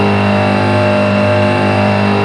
rr3-assets/files/.depot/audio/sfx/electric/mp4x_on_low_6000rpm.wav
mp4x_on_low_6000rpm.wav